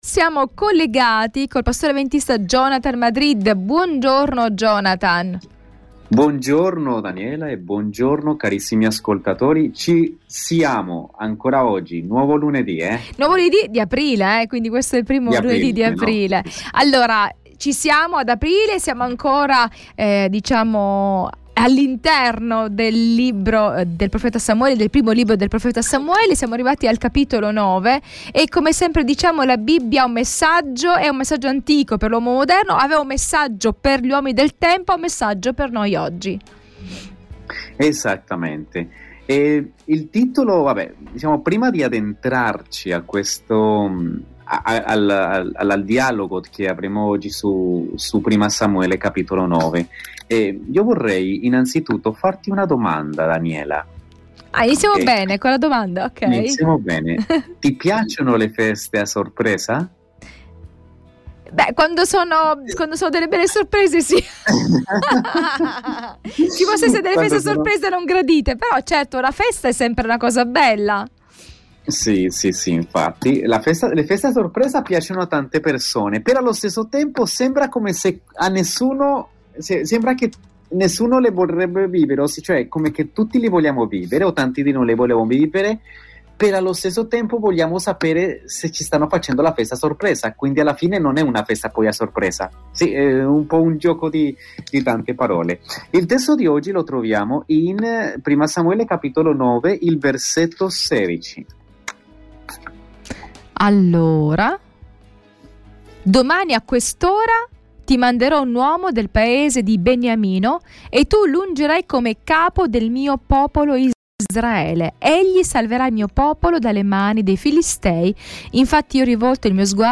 pastore avventista ci racconta come gli avvenimenti guidati da Dio hanno portato a questa scelta.